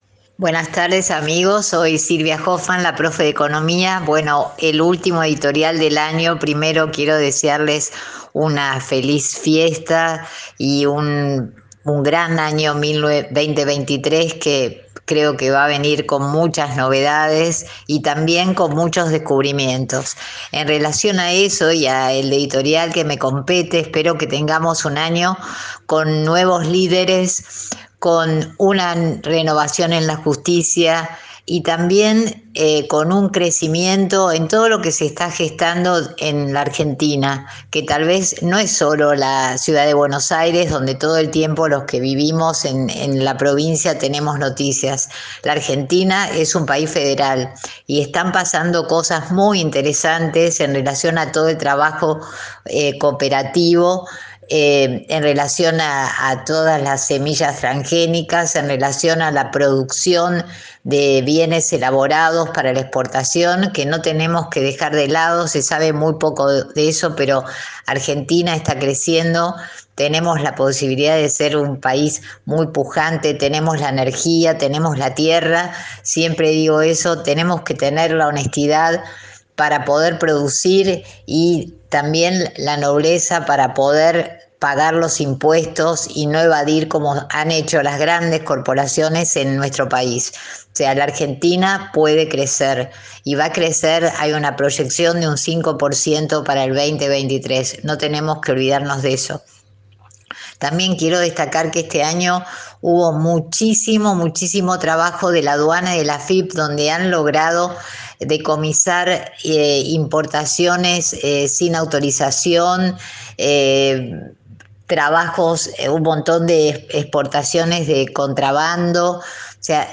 NOTICIAS DE LA PROFE DE ECONOMÍA